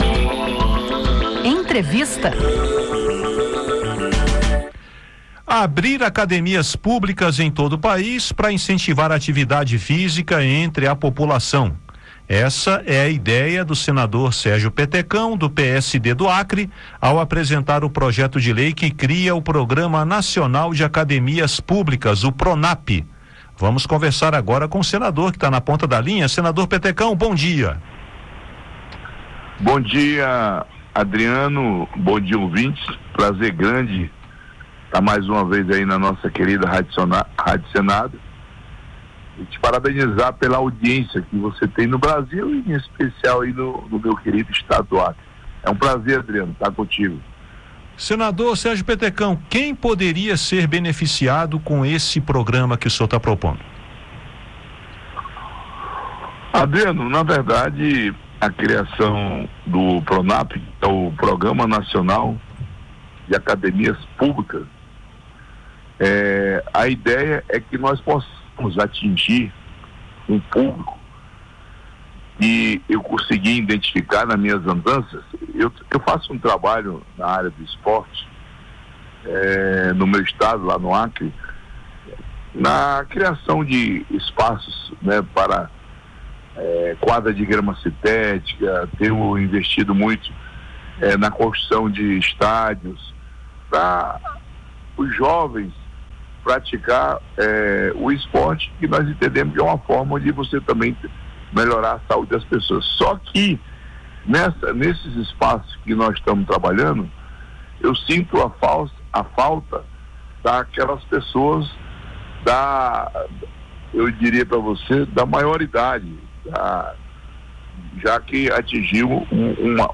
O senador Sérgio Petecão (PSD-AC) apresentou um projeto de lei que cria o Programa Nacional de Academias Públicas (Pronap), com o objetivo de ampliar o acesso da população à atividade física e fortalecer a prevenção em saúde. A proposta prevê apoio técnico e financeiro da União para a implantação e manutenção de espaços gratuitos, com prioridade para regiões de maior vulnerabilidade social. Para falar sobre o alcance do programa, as regiões beneficiadas e o financiamento da iniciativa, a Rádio Senado entrevista o senador Sérgio Petecão.